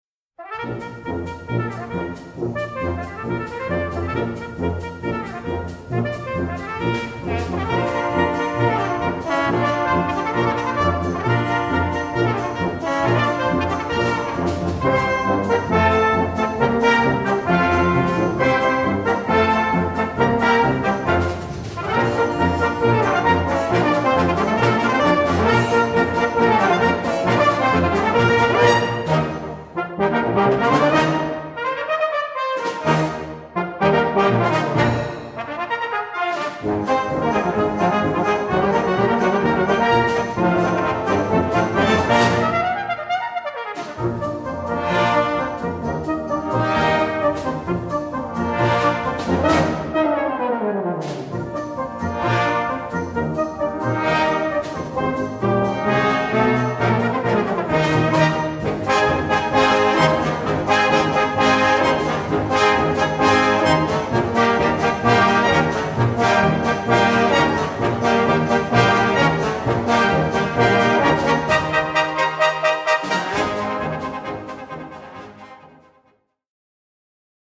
4:00 Minuten Besetzung: Blasorchester PDF